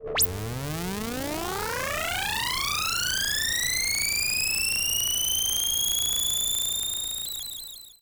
Roland E Noise 07.wav